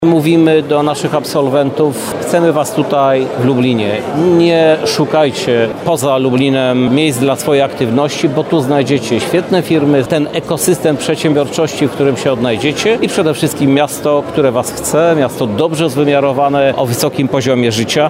Podczas gali prezydent Lublina Krzysztof Żuk, zwrócił uwagę na rozwój Lublina: